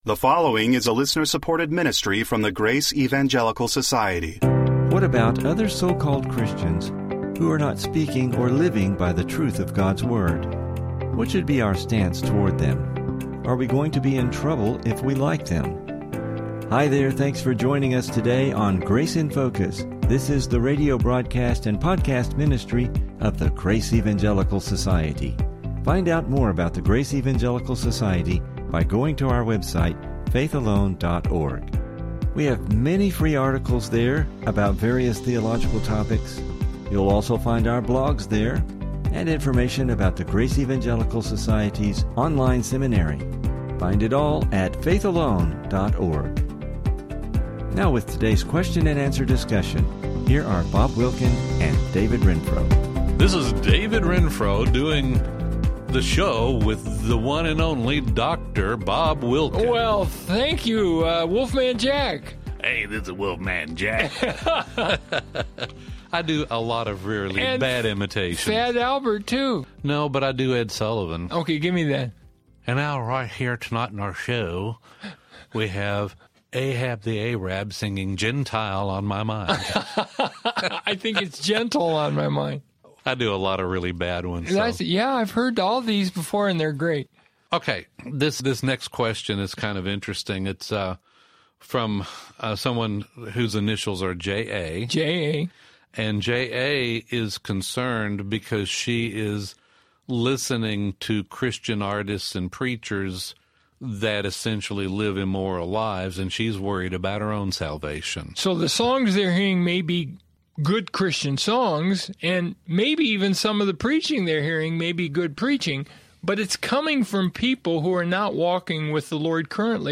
Please listen for some interesting Biblical discussion regarding these things!